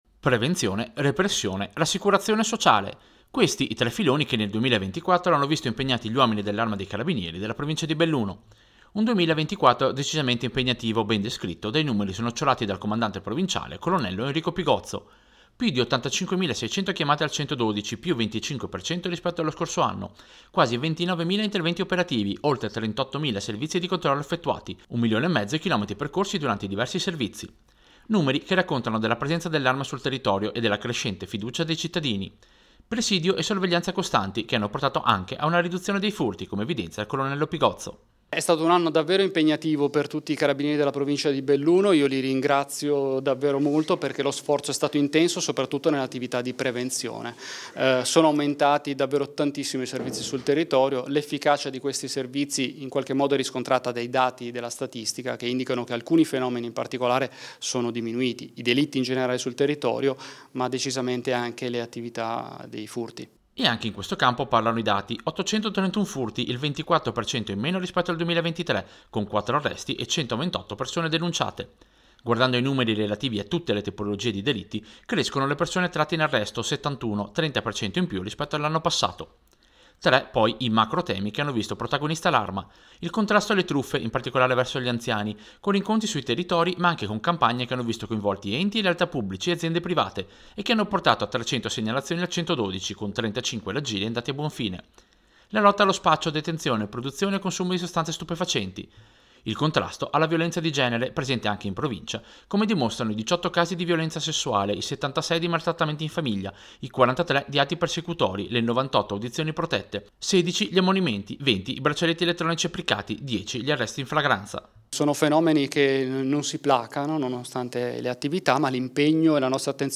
Servizio-Bilancio-Carabinieri-2024.mp3